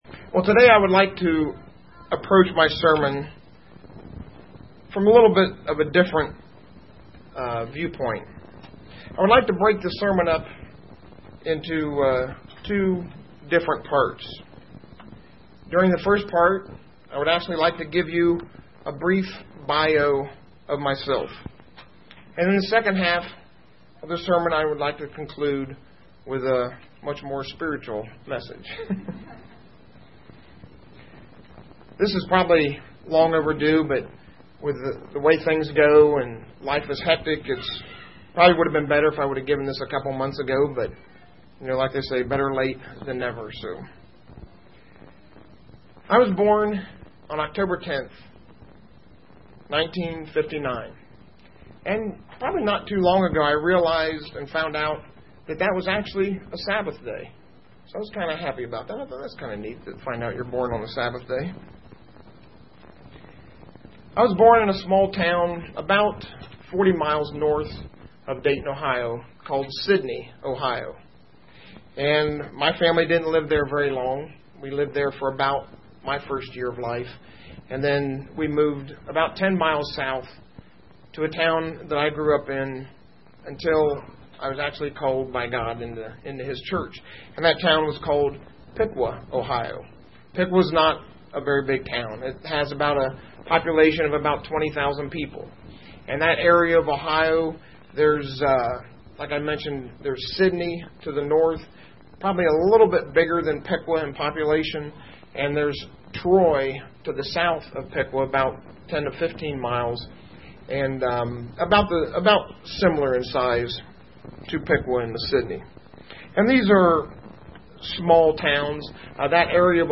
Given in Indianapolis, IN Ft. Wayne, IN
UCG Sermon Studying the bible?